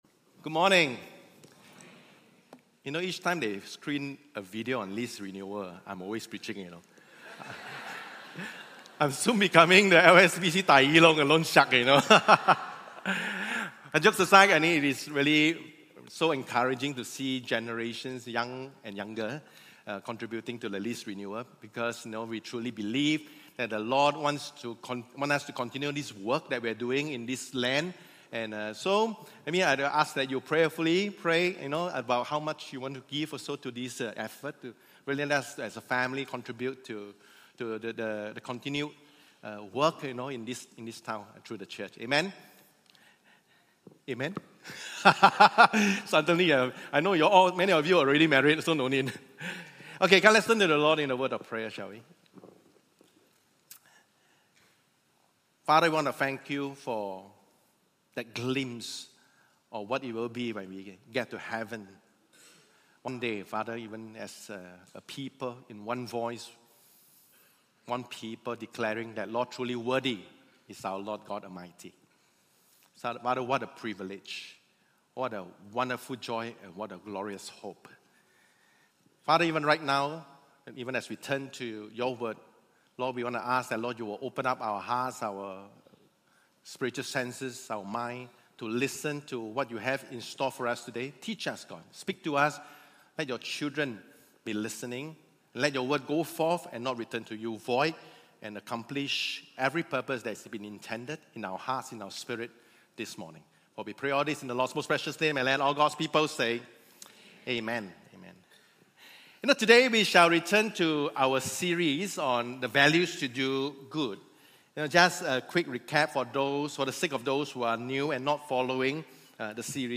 Sermon Audio (.mp3)